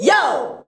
YO.wav